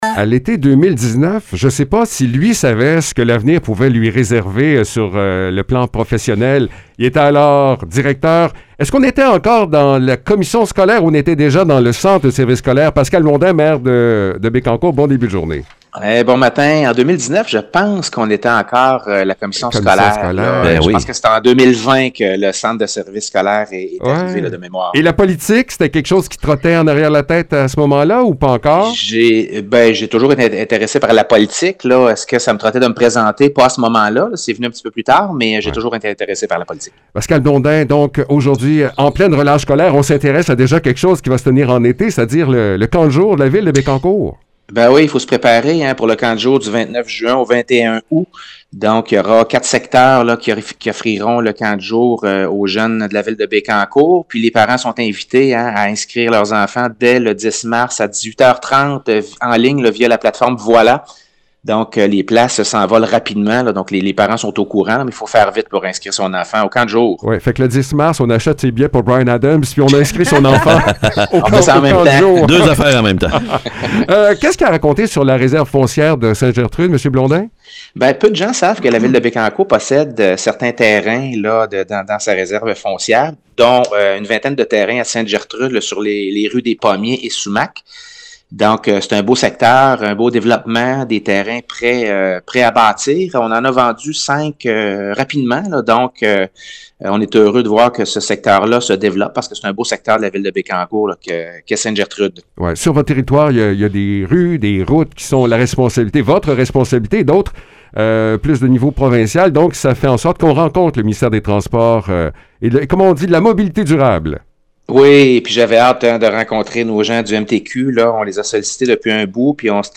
Avec Pascal Blondin, maire de Bécancour, on parle des camps de jour, alors que les inscriptions partent à toute vitesse. On profite aussi de l’occasion pour découvrir que plusieurs terrains prêts à bâtir sont actuellement disponibles pour ceux qui rêvent de s’installer dans la région.